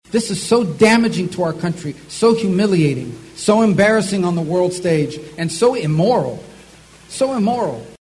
Speaking at an Iowa GOP fundraiser at the Mason City Municipal Airport Monday night, Rubio said the pullout plan was based on assumptions that were not real.